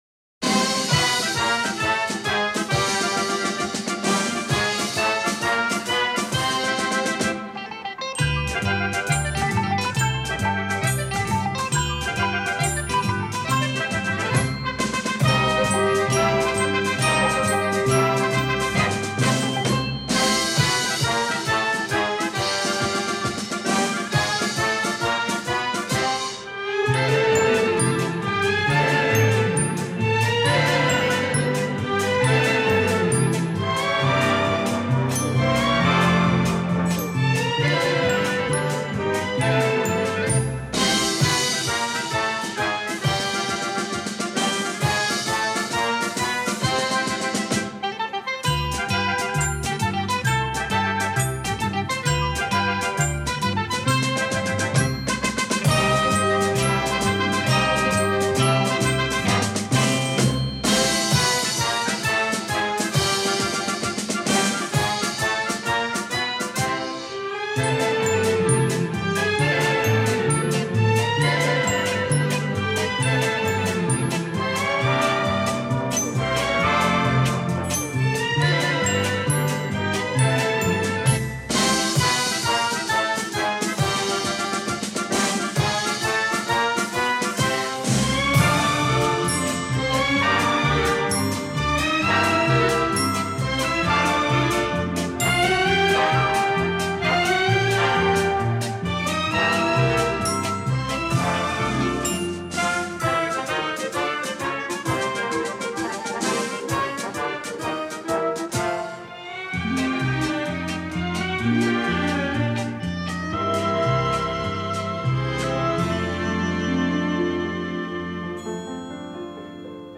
colonna sonora[1